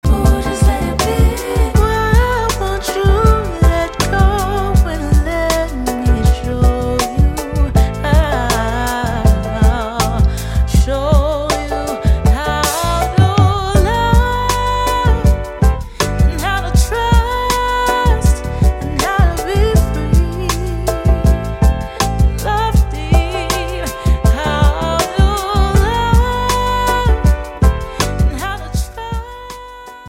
rich Neo-soul, Highlife, and Jazz influences
ethereal vocals and refined jazz arrangements